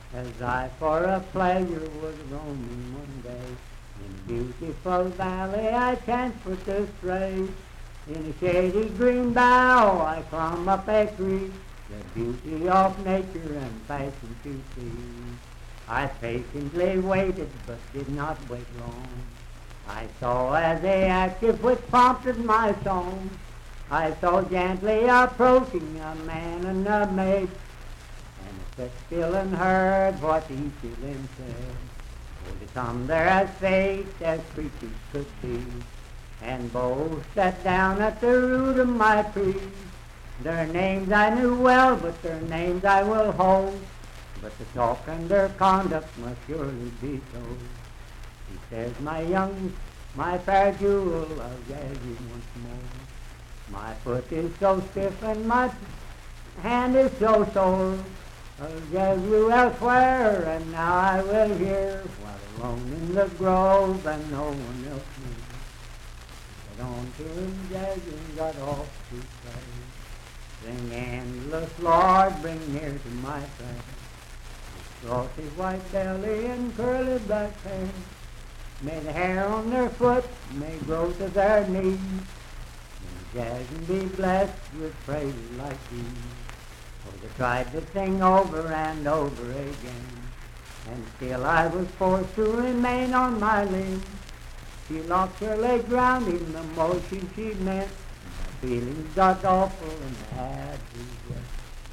Unaccompanied vocal music and folktales
Bawdy Songs
Voice (sung)
Wood County (W. Va.), Parkersburg (W. Va.)